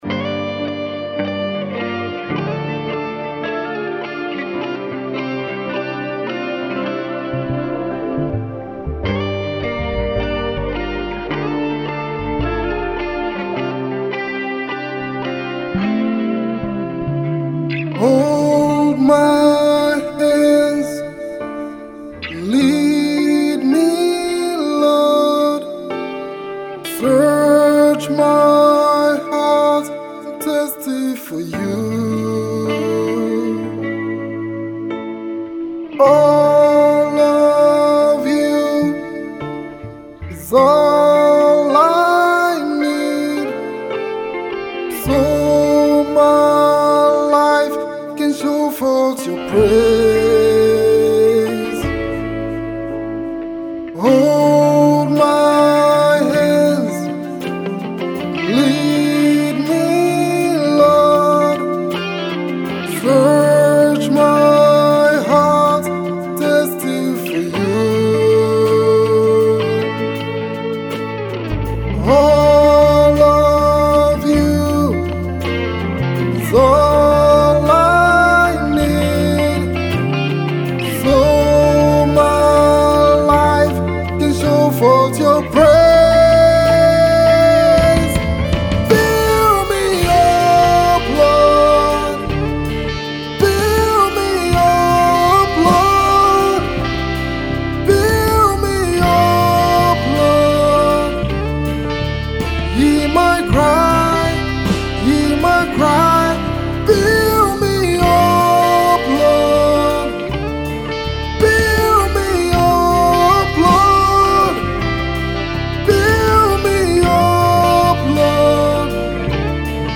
Delta State born Gospel Singer
spirit filled song